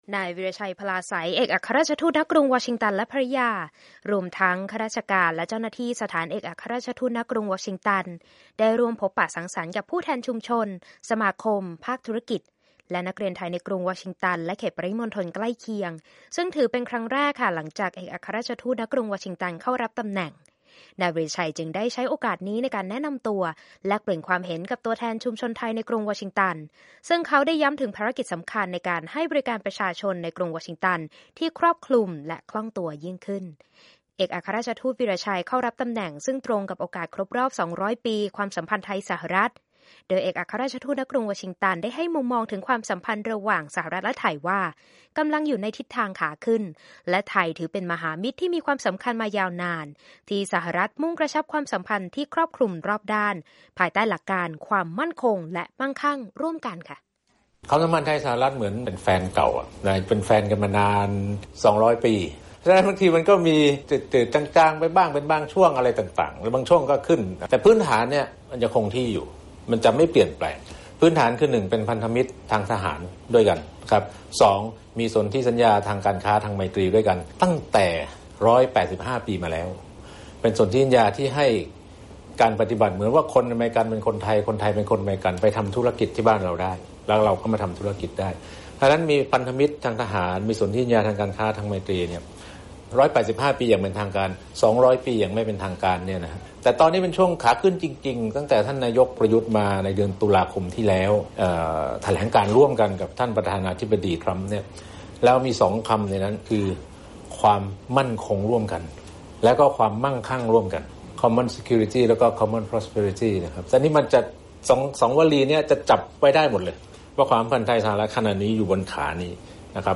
Thai Ambassador Interview